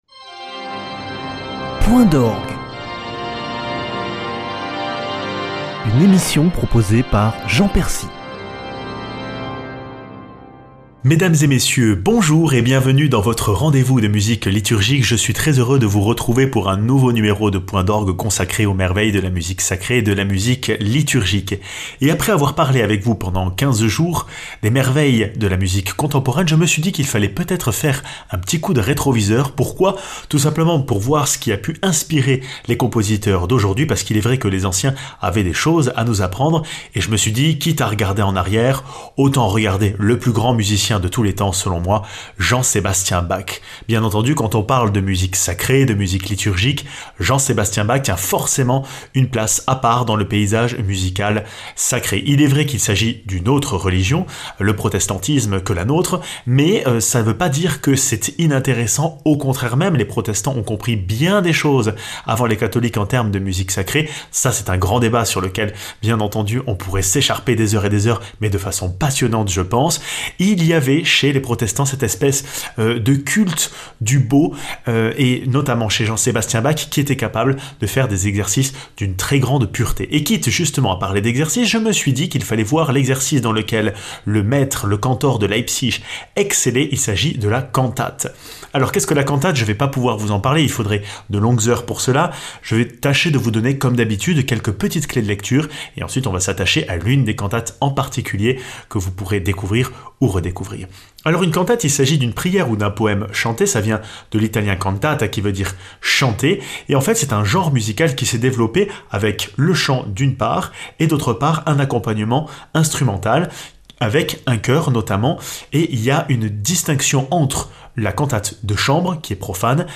Ecrite pour la fête de la Visitation, cette cantate de Bach est un hymne à la vie et transporte son auditeur dans une joie rarement atteinte dans une œuvre liturgique.